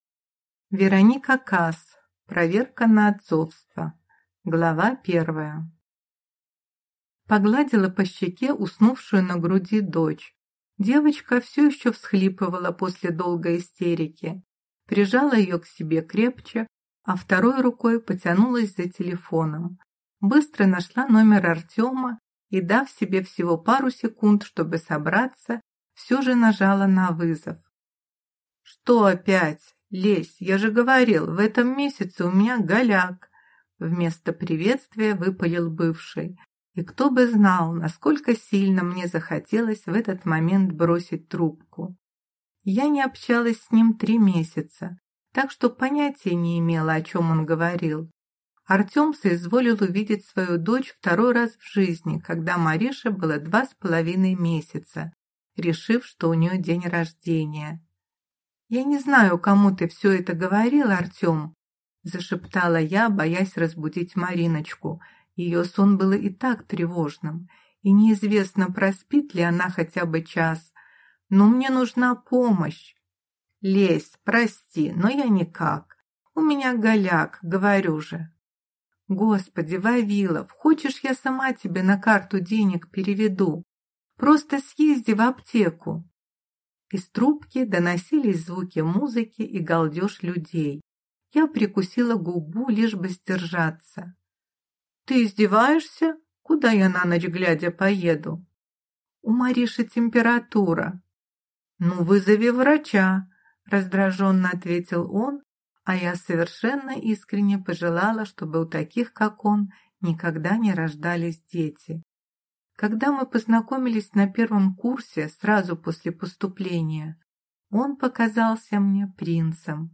Аудиокнига Проверка на отцовство | Библиотека аудиокниг
Прослушать и бесплатно скачать фрагмент аудиокниги